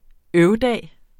Udtale [ ˈœw- ]